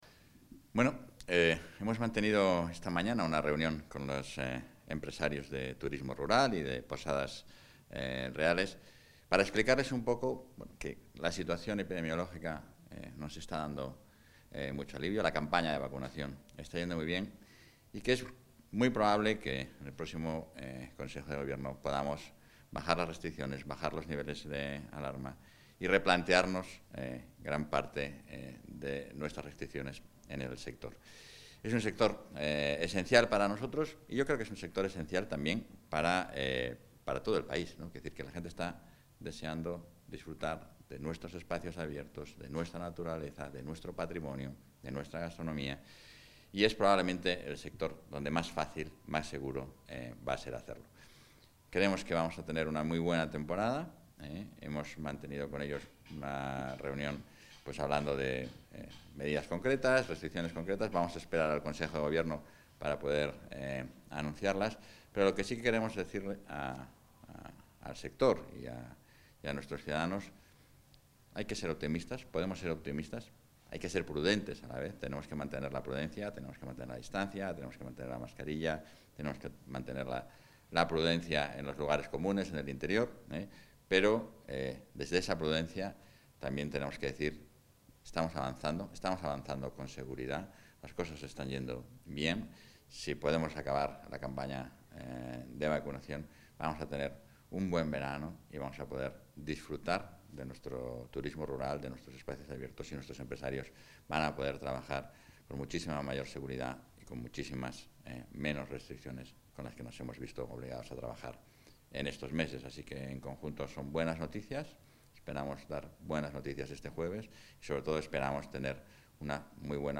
Valoración del vicepresidente de la Junta.